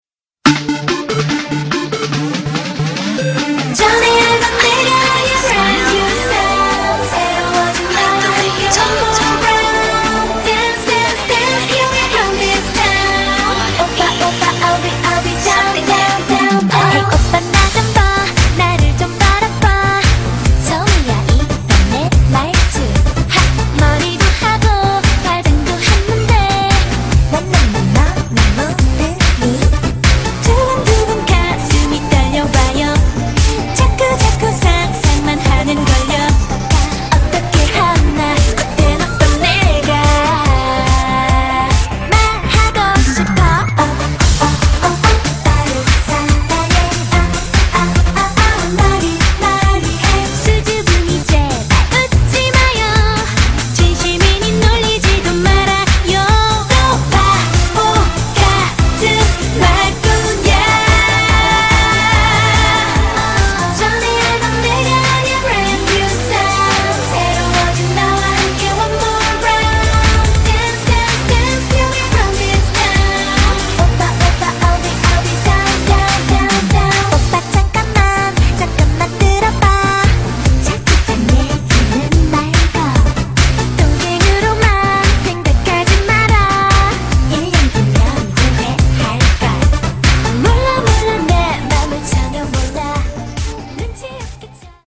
Korean super girl group
thumping electronica-driven pop
tighter harmonies